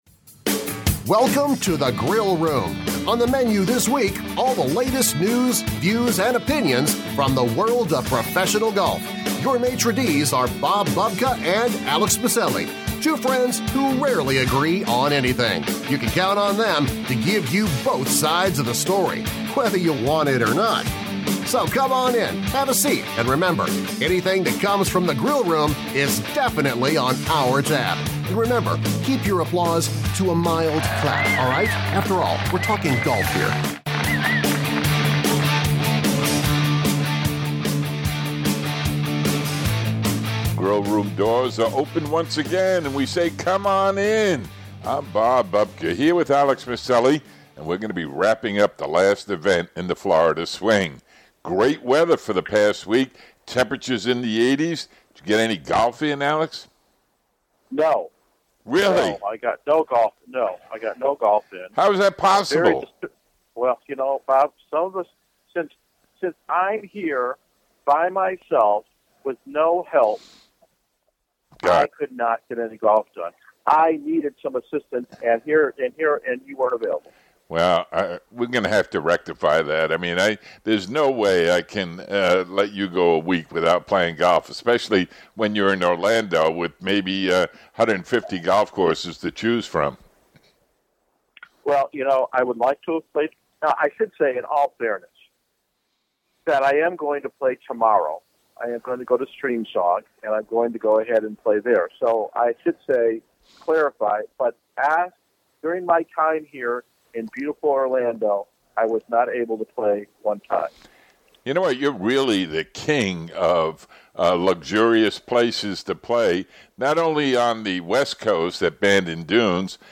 Two wins on the PGA Tour - both in Arnold Palmer's Bay Hill. Feature Interviews